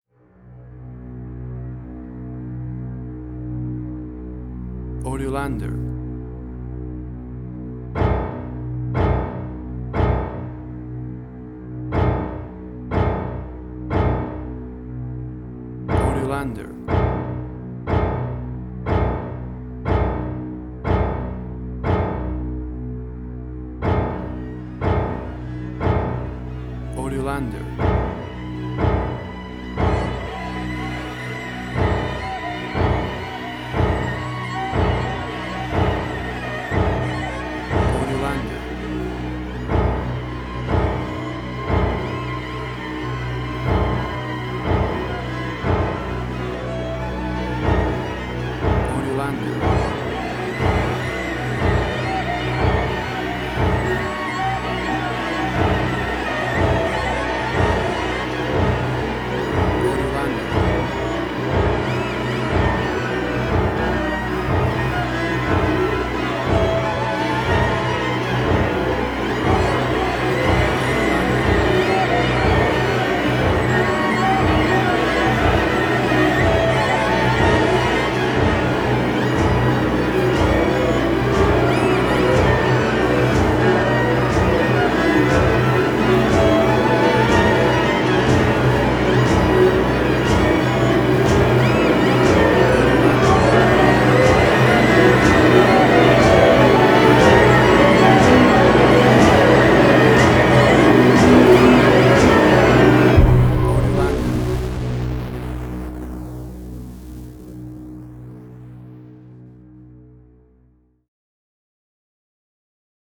Dissonance Similar The Hurt Locker Fear
Tempo (BPM): 60